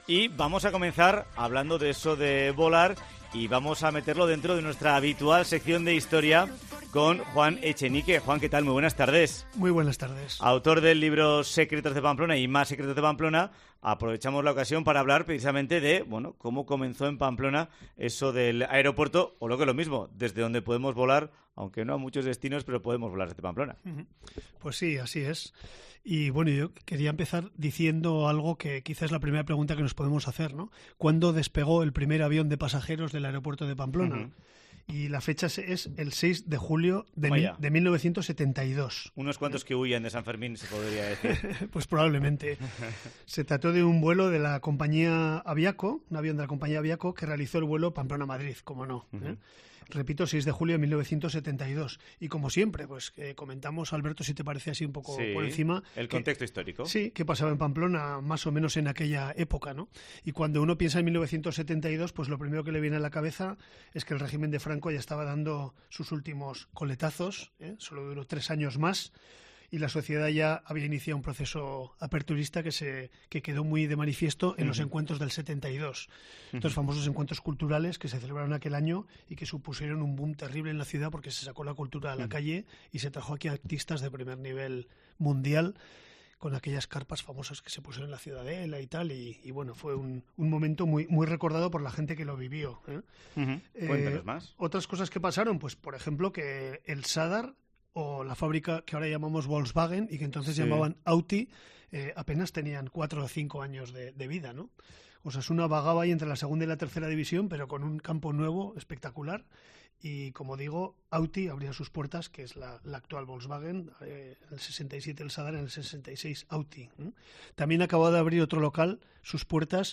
La entrevista está enmarcada dentro del monográfico sobre volar que hemos realizado en COPE Navarra ( LEER Y ESCUCHAR MÁS ) Datos: Primer vuelo regular de pasajeros: el 6 de julio de 1972 un avión de la compañía Aviaco realiza vuelo Pamplona-Madrid.